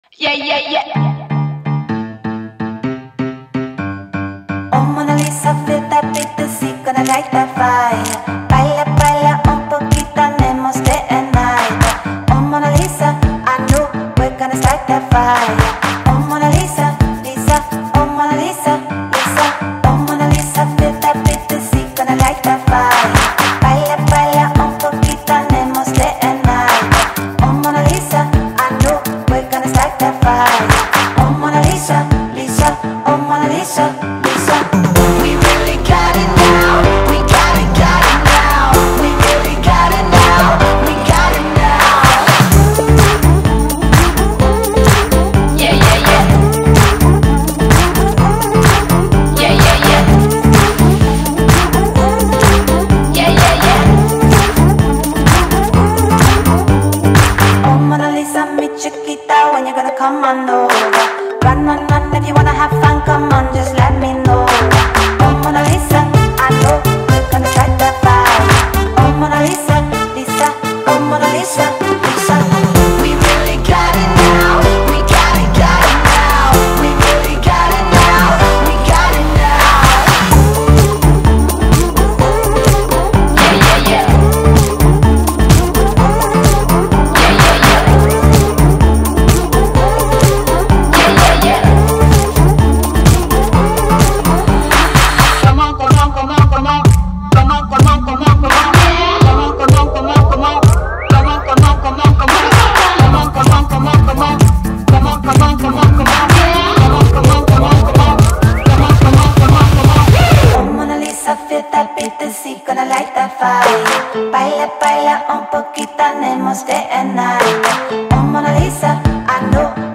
завораживая своим мелодичным звучанием и глубокими текстами.